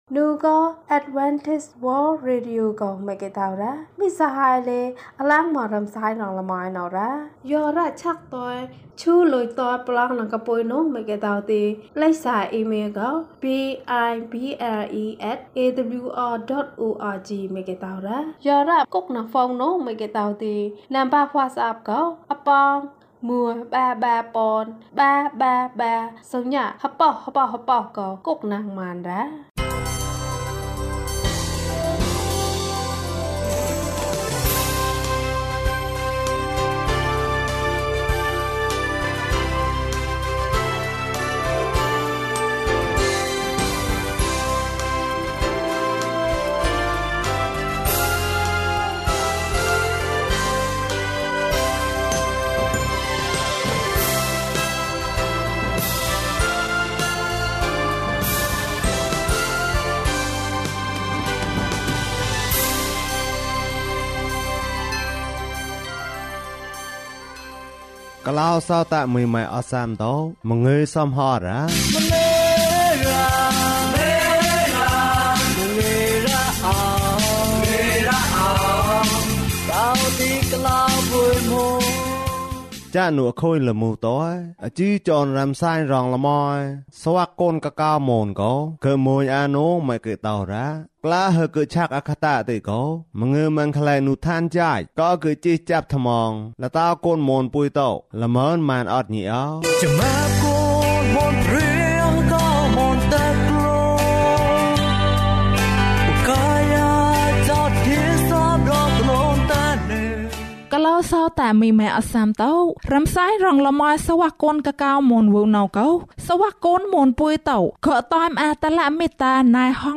ငါ့ထံသို့ လာကြလော့။ ကျန်းမာခြင်းအကြောင်းအရာ။ ဓမ္မသီချင်း။ တရားဒေသနာ။